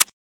sounds / weapons / _dryfire / gl_dry.ogg